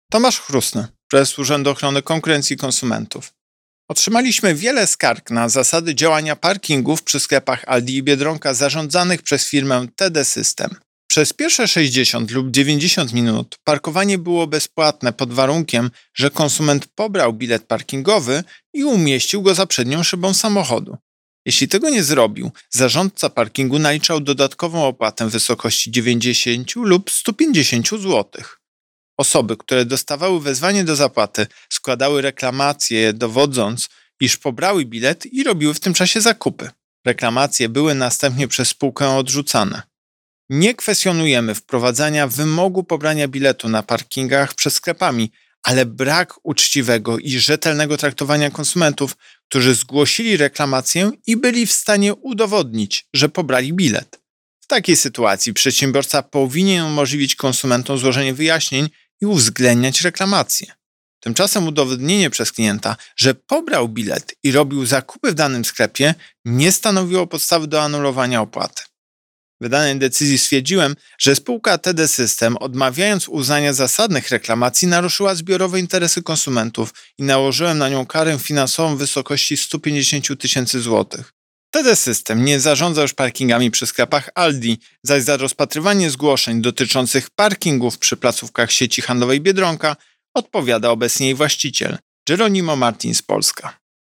Wypowiedź Prezesa UOKiK Tomasza Chróstnego z 9 lipca 2021 r..mp3